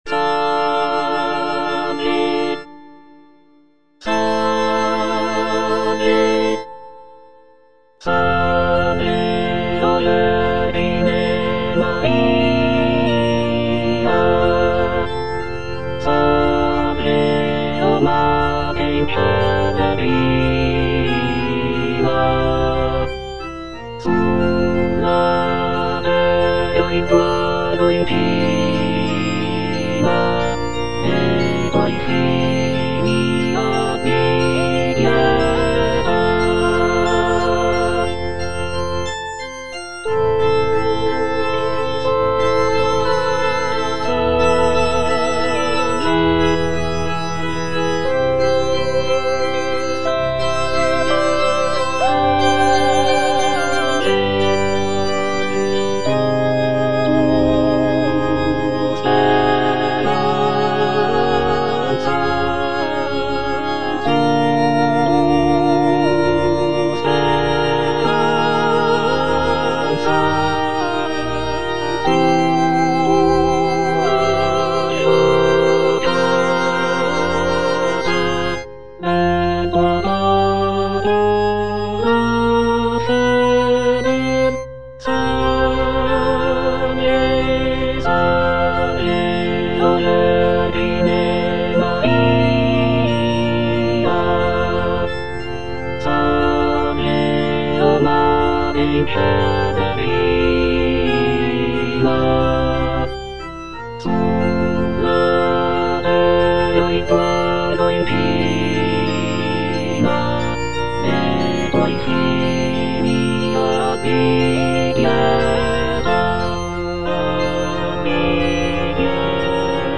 G. ROSSINI - SALVE O VERGINE MARIA (All voices) Ads stop: auto-stop
The music is characterized by its serene and devotional atmosphere, with lush harmonies and expressive melodies.